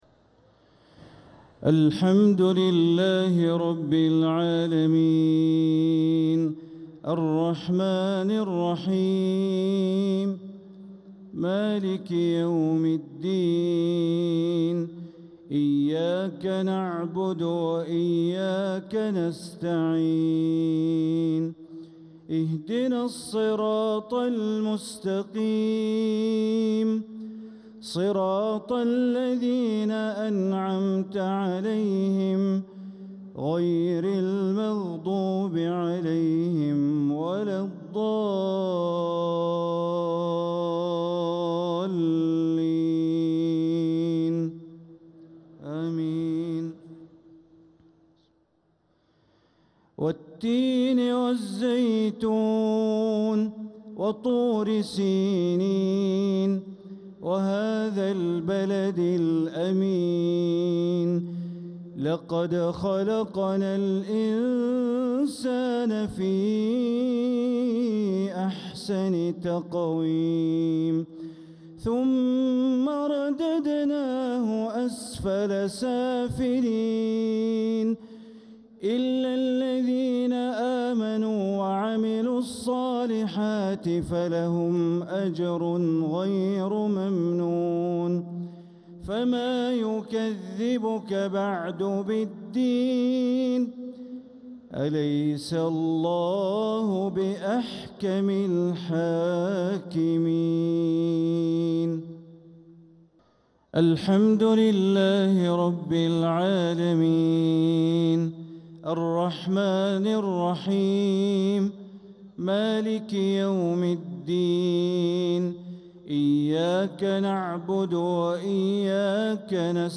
تلاوة جميلة لسورتي التين وقريش للشيخ بندر بليلة | عشاء ١١ رمضان ١٤٤٧هـ > 1447هـ > الفروض - تلاوات بندر بليلة